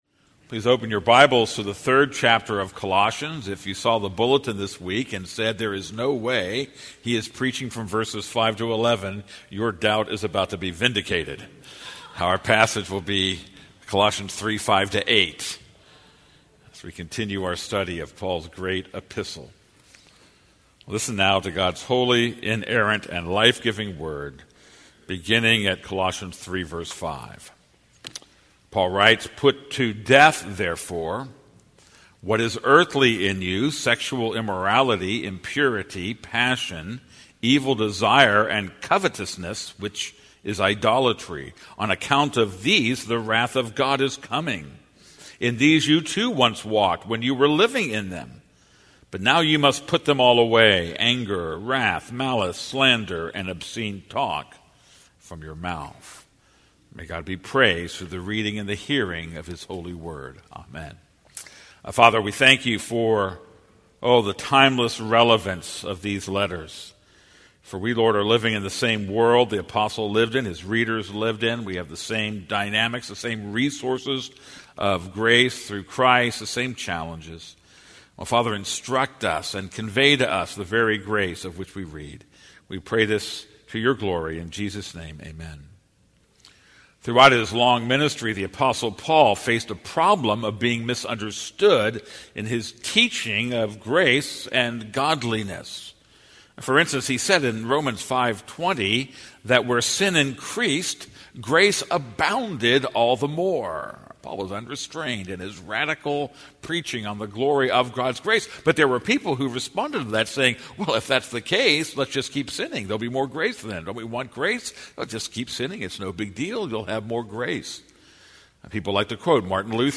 This is a sermon on Colossians 3:5-8.